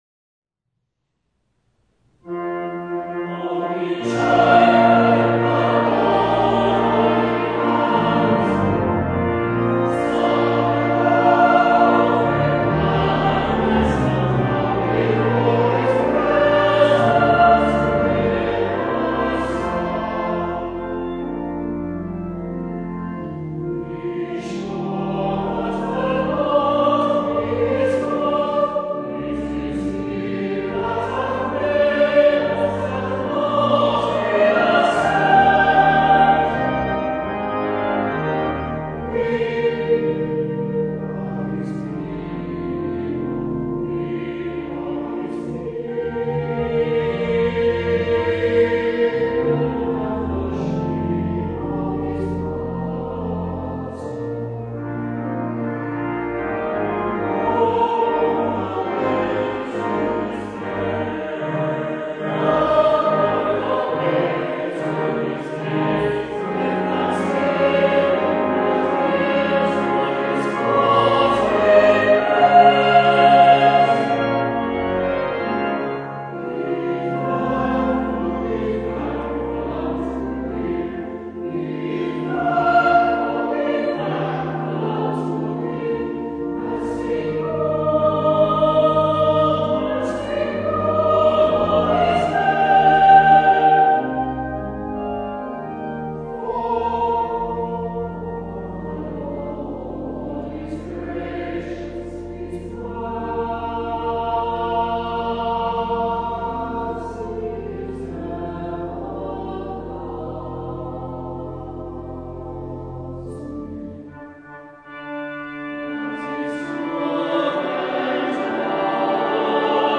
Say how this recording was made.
For those recordings made during live services, the permission of the appropriate authority was sought and obtained.